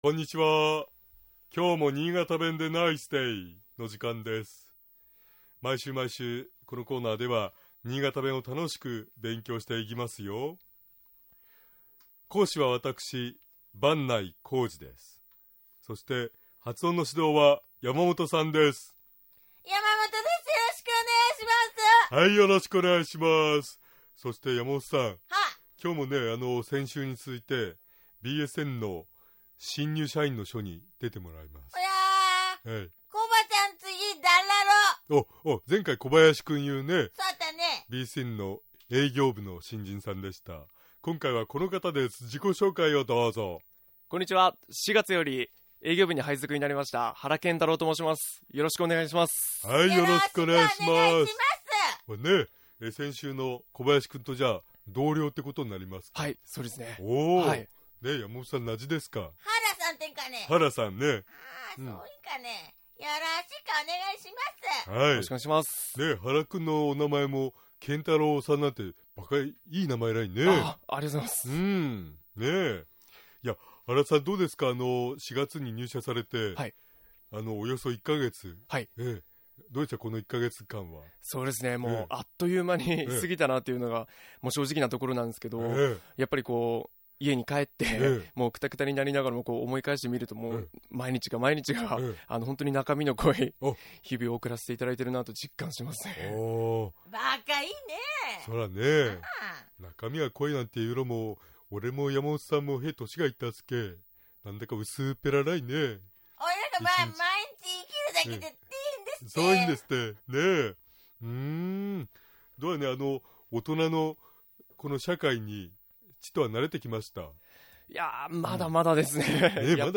先週新潟弁は「え」と「い」の発音がはっきりしない事を勉強しましたが、 上越中越下越と言う場合も、 「越」の部分「いつ」に近い音になる為、「上いつ中いつ下いつ」と発音します。 また、「佐渡」については、 共通語のように「佐渡」と、頭の「さ」にアクセントを置くのではなく、 「佐渡」という風に、お尻の「ど」にアクセントを置いて発音します。